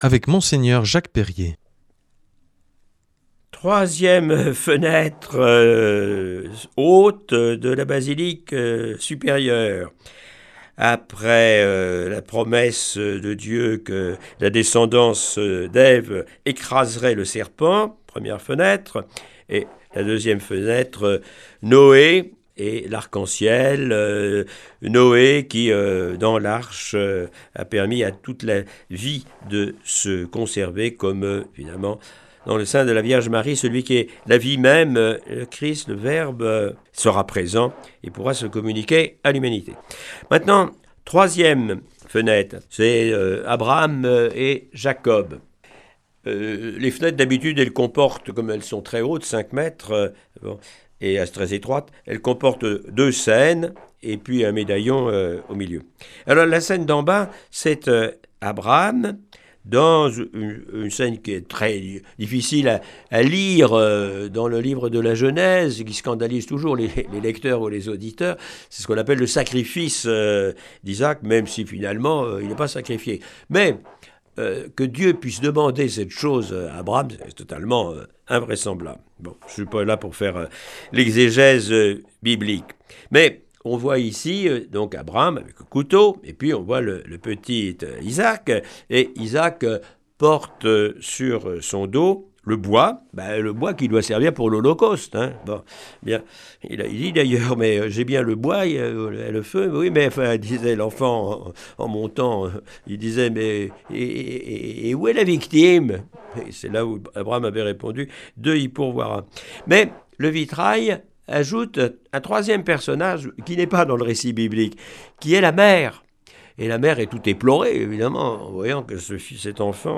Avec Mgr Jacques Perrier pour guide, nous allons découvrir cette semaine les vitraux ornant la partie haute de la basilique de l’Immaculée Conception au Sanctuaire de Lourdes.